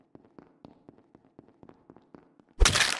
Among Us Walking On Glass Kill Sound Effect Free Download
Among Us Walking On Glass Kill